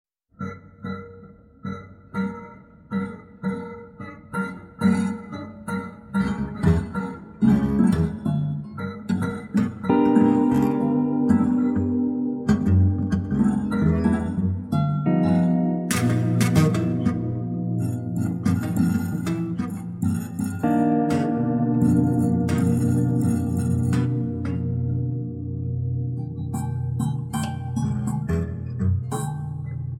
Acoustic, Electric Guitar and SuperCollider
Acoustic, Electric Guitar and Percussion
is the combination of prepared acoustic guitar duet